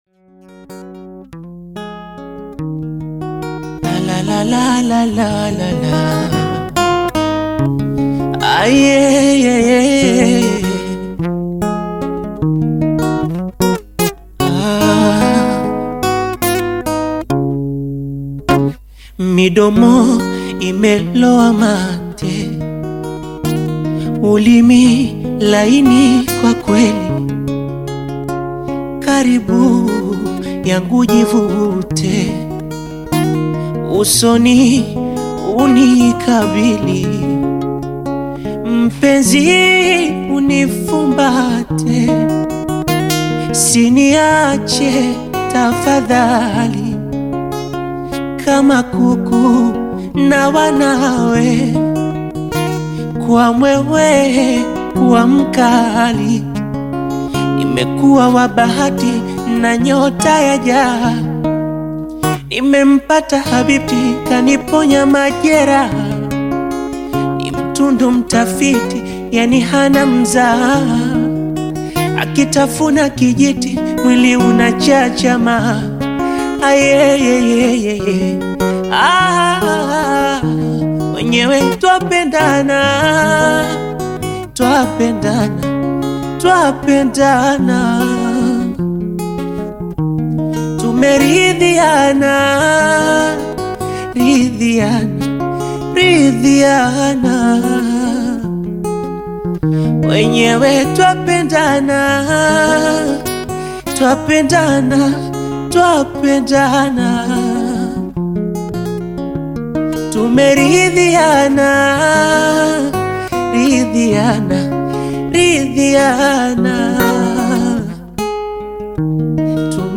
Bongo Flava
acoustic version
African Music